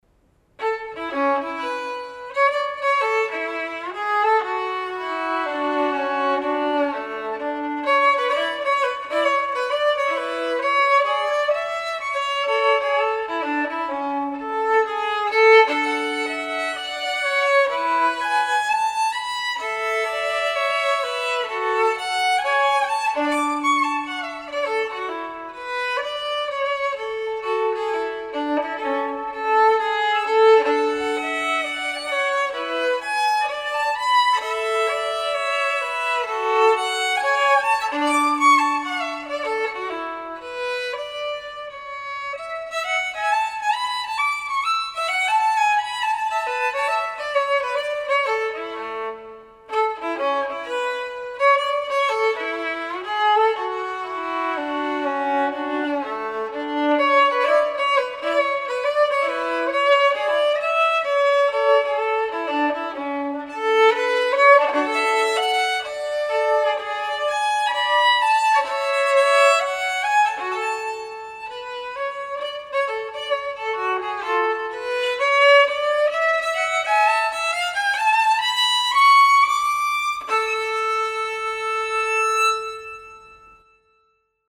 スキップしながらお散歩しているイメージです。